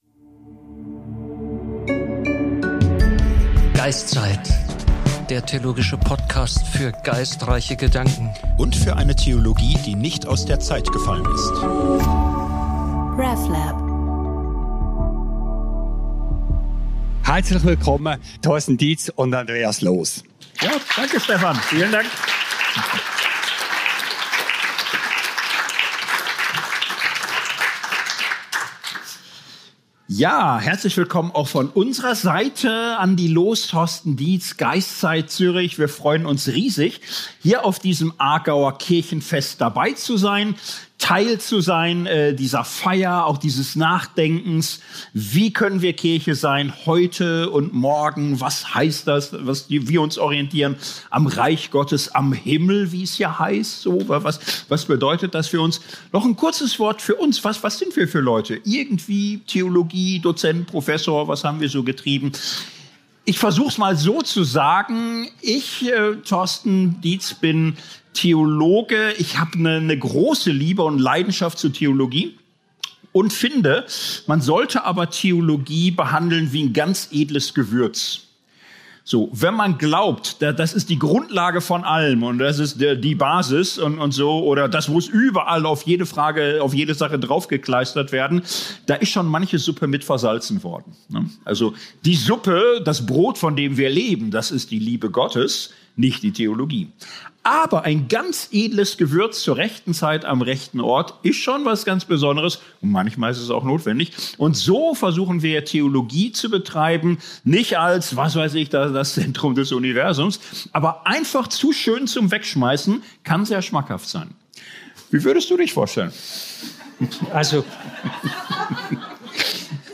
Mit diesen Fragen war Geist.Zeit am 13. September 2025 zu Gast auf dem grossen Kirchenfest der reformierten Kirche Aargau.